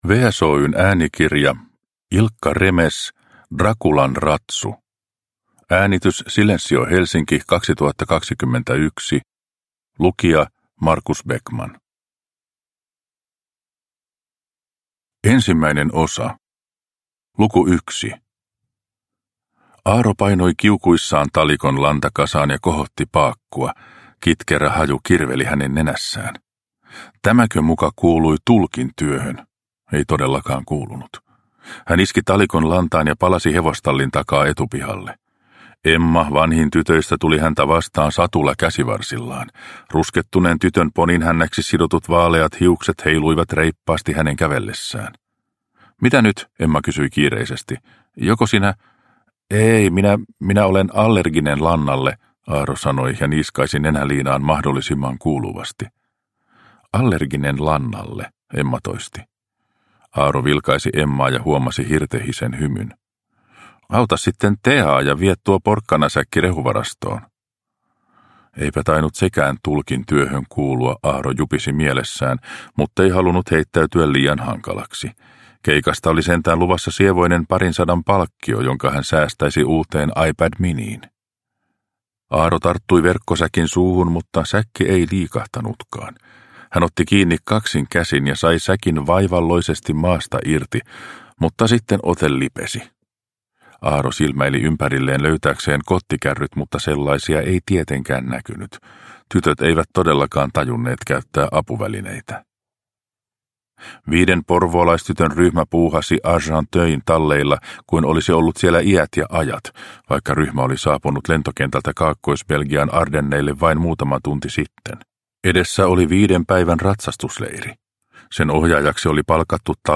Draculan ratsu – Ljudbok – Laddas ner